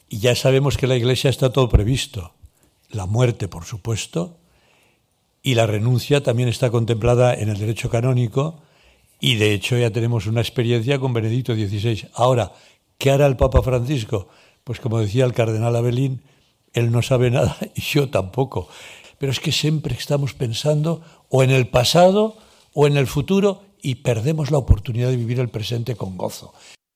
Escolta l'arquebisbe de Barcelona, Joan Josep Omella